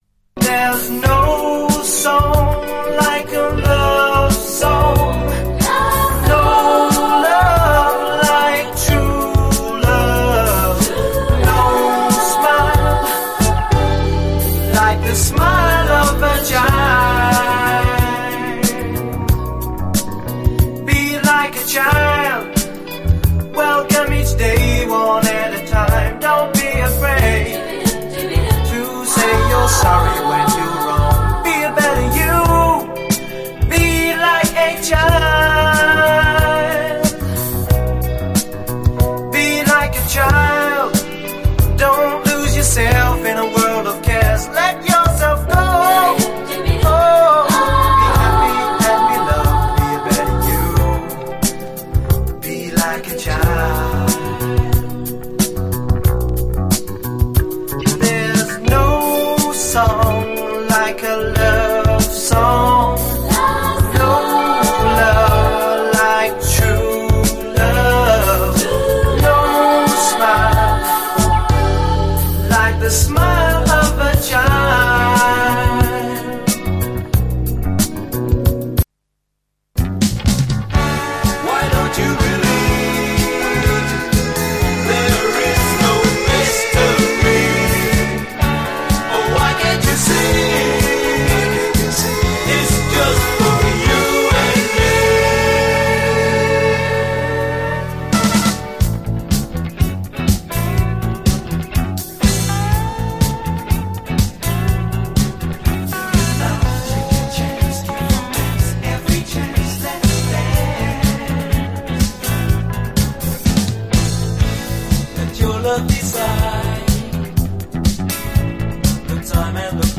異常に洗練されたサウンドで圧倒するUK産メロウ・モダン・ソウル！